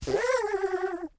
Block Ferdinand Sound Effect
Download a high-quality block ferdinand sound effect.